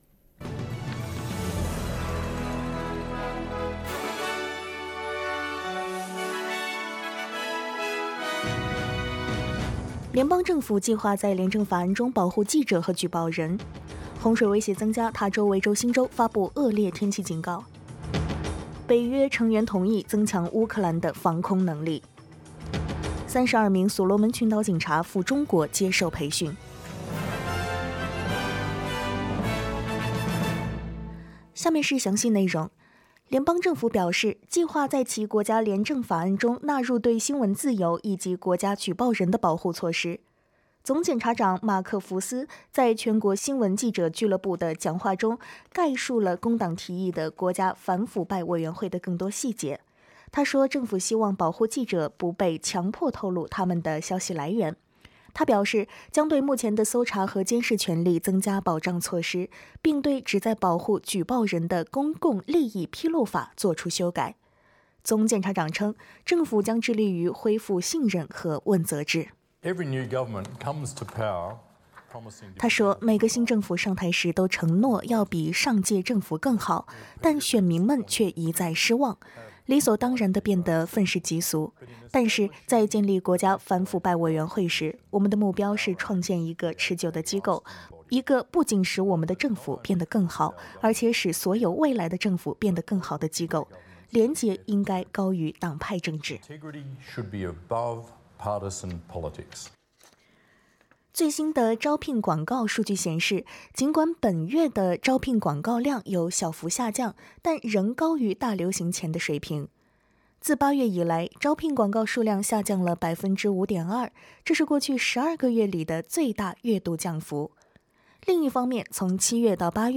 SBS早新闻（10月13日）
请点击收听SBS普通话为您带来的最新新闻内容。